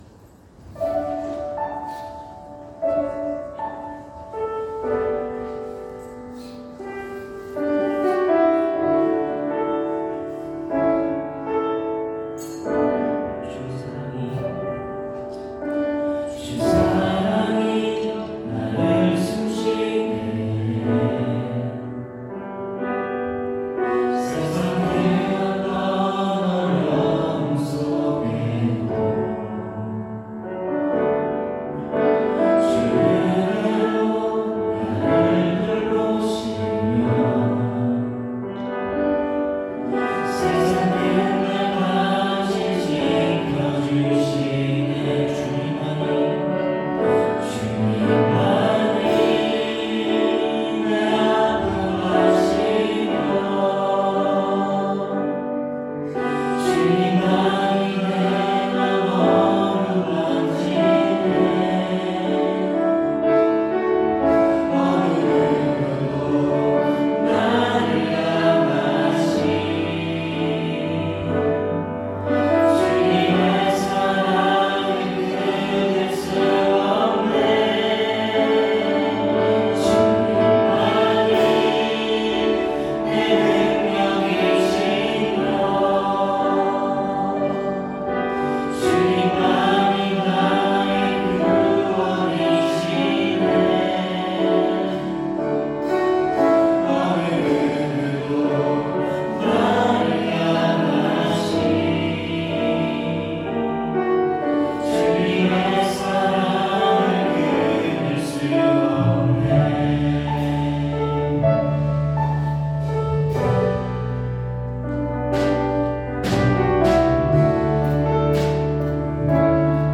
2026년 01월 26일 주일찬양 – 셰필드한인교회